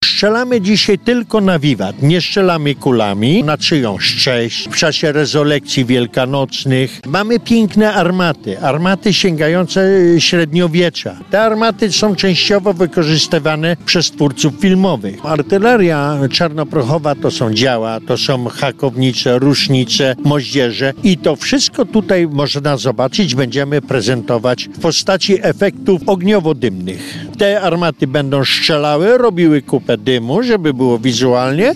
Nadbużański Przegląd Artylerii Czarnoprochowej odbywa się w Terespolu.